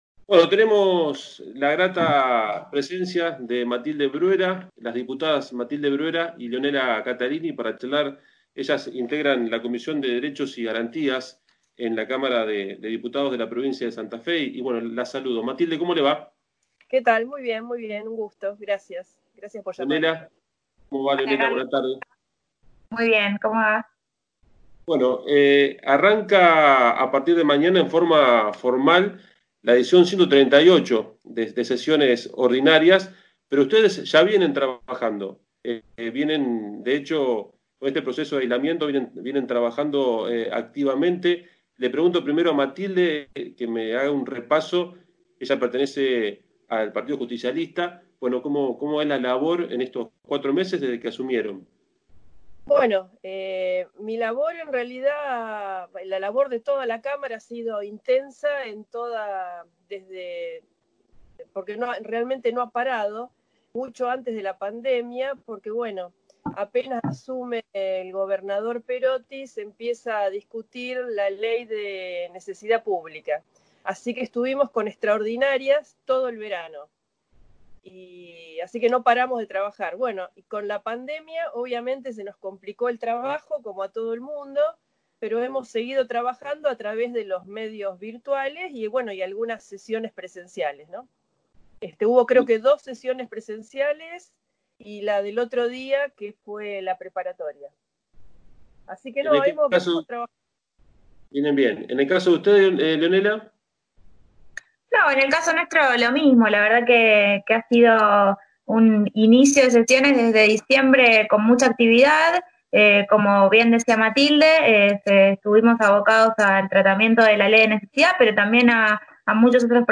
Las Diputadas Lionella Cattalini (Frente Progresista) y Matilde Bruera (Partido Justicialista) analizaron en Buena Jornada (Pop Radio 96.1) la igualdad entre hombres y mujeres en las cámaras, la población carcelaria y la relación política en tiempos de pandemia.